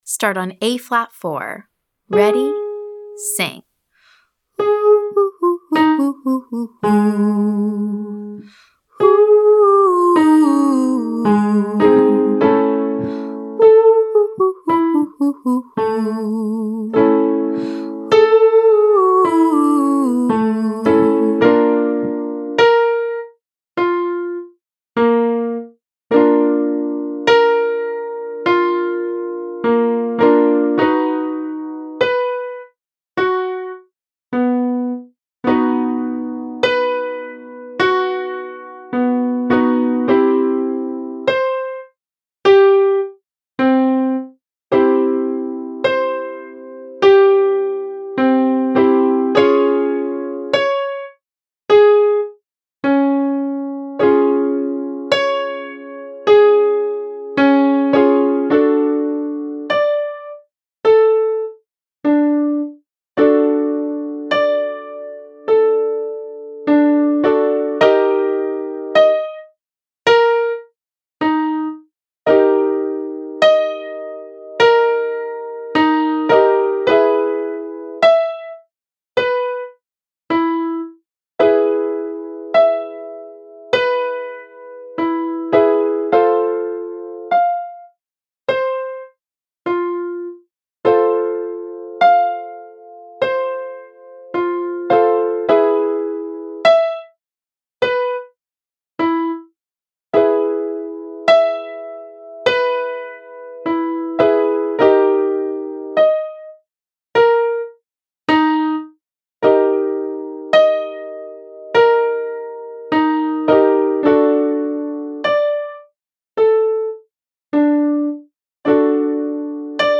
From: Daily High Voice Vocal Agility Warmup
1. Exercise 1: Descending major scale staccato, then legato.